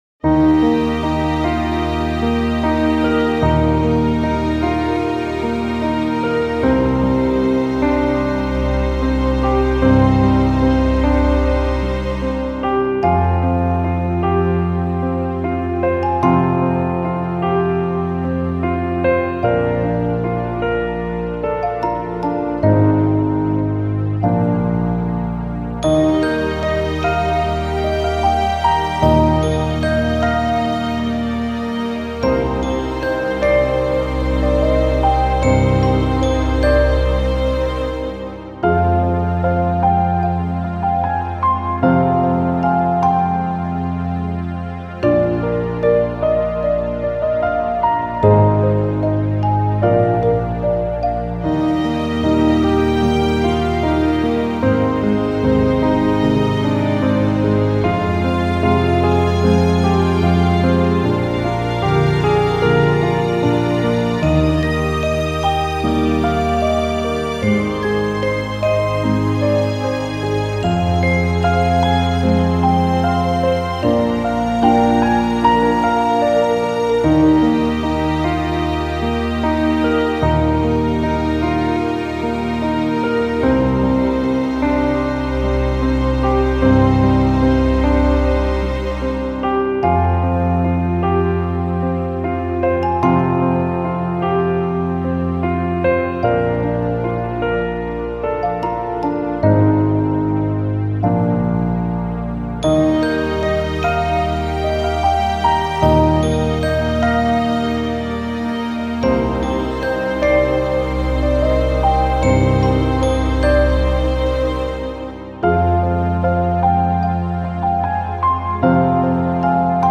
穏やかで優しい雰囲気の、ピアノ中心としたBGMです。
BGM ピアノ ストリングス リラックス 夏 秋 静か 優しい 落ち着く 幻想的